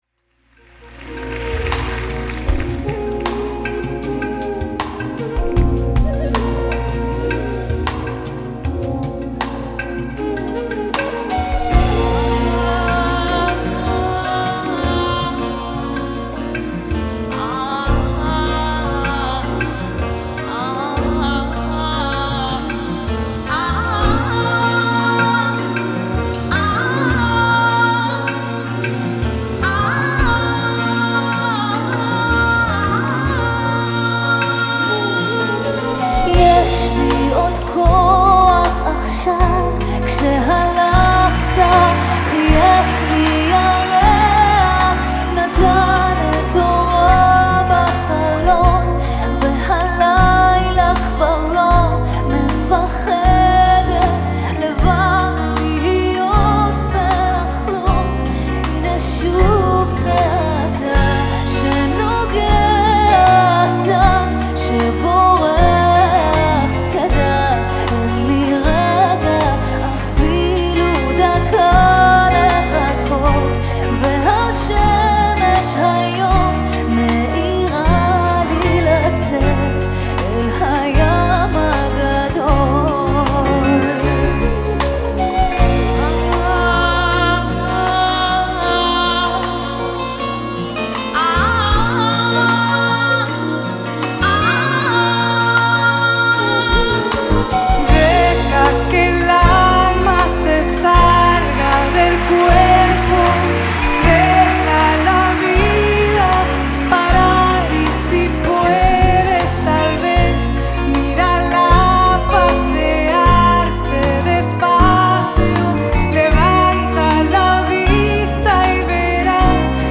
Style: Folk